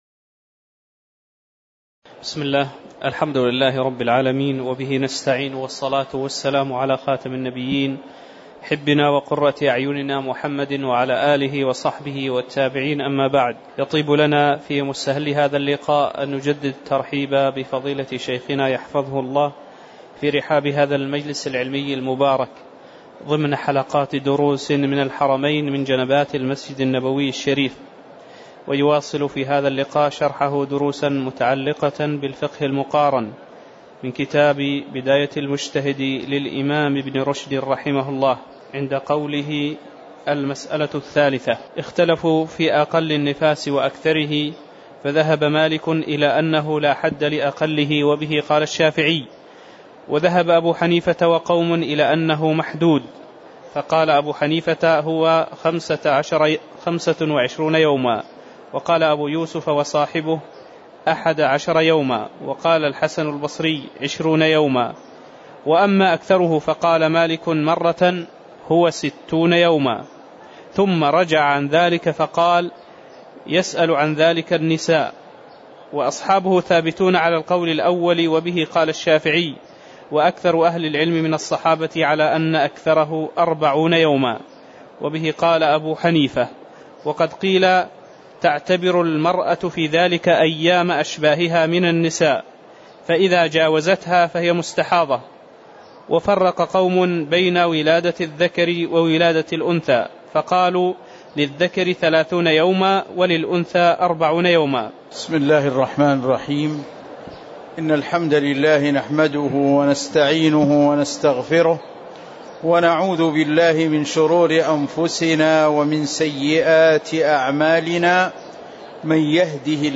تاريخ النشر ١٤ جمادى الأولى ١٤٤٠ هـ المكان: المسجد النبوي الشيخ